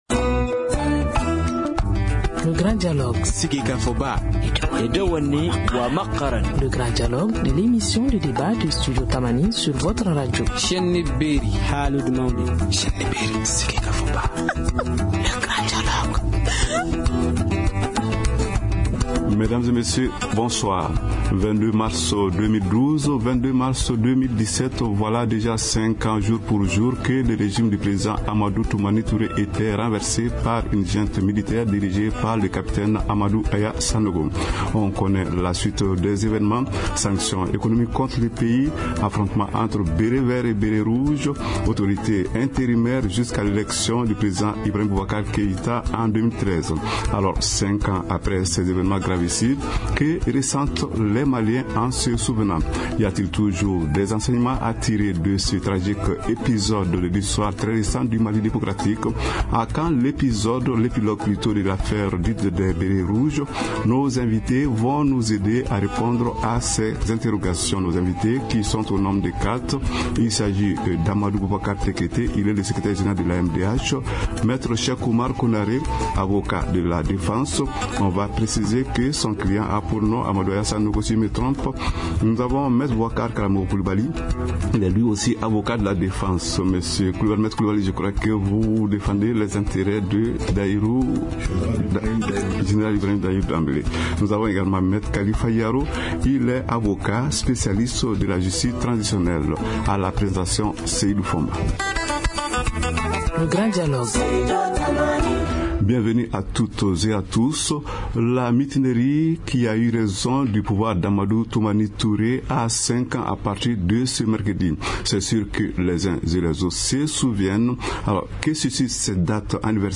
Nos invités vont nous aider à répondre à ces questions.